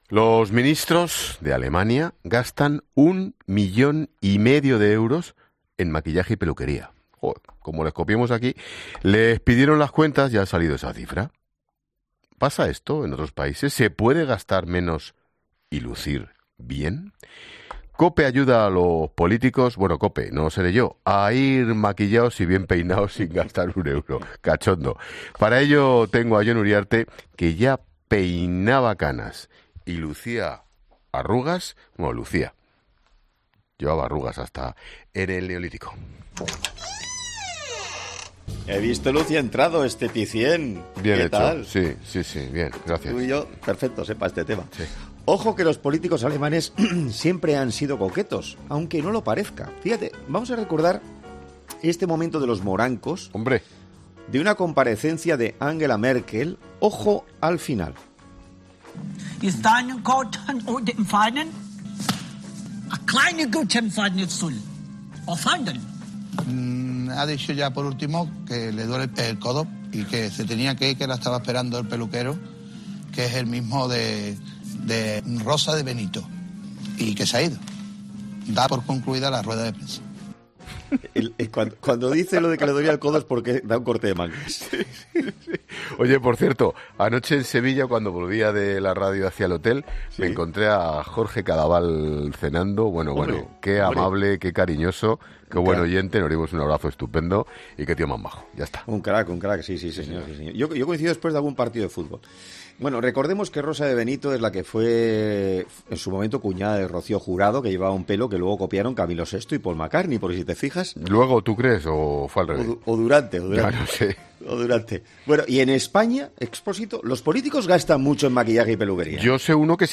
Así, comenzaba a sonar en COPE un gag del dúo humorístico en el que parodian al traductor de la ex canciller alemana y su reacción tras un corte de mangas: “Ha dicho por último que le duele el codo y que se tenía que ir que le estaba esperando el peluquero, que es el mismo que Rosa de Benito y que se ha ido, da por concluida la rueda de prensa”.